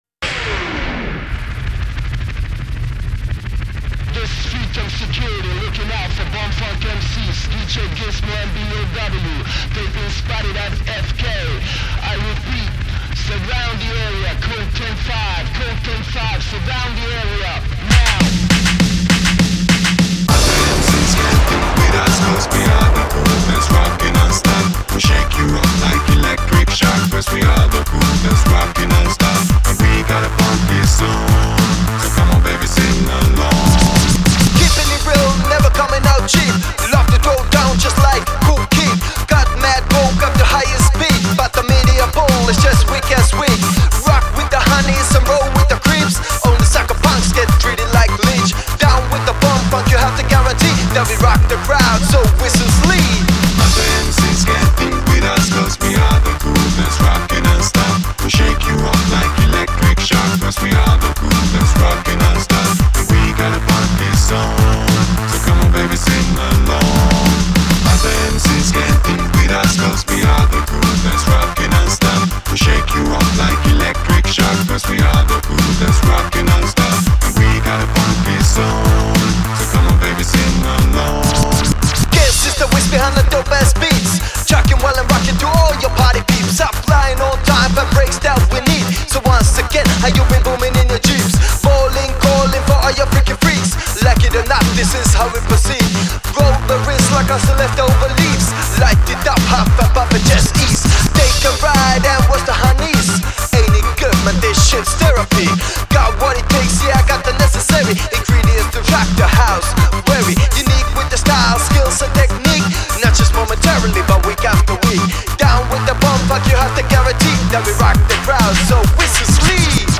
Жанр: хип-хоп-реп